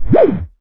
1705R SYN-FX.wav